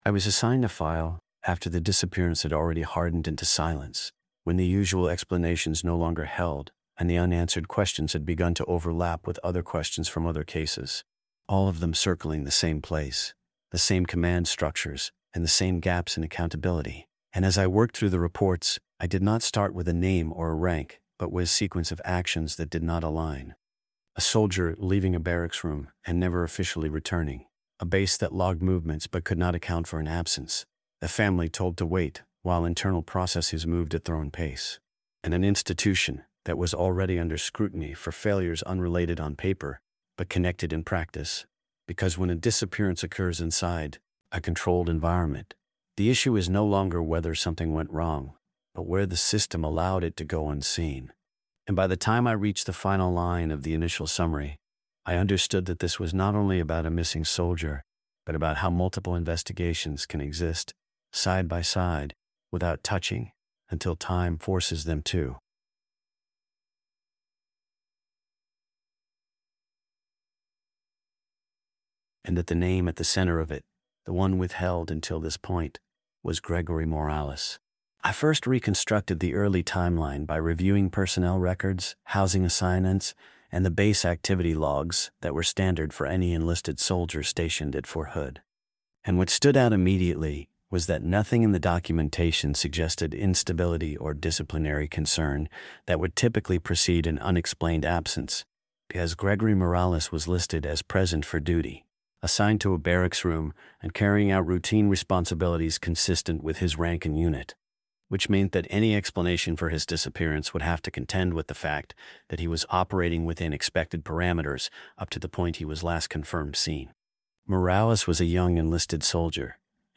Told from the perspective of a first-person detective narrator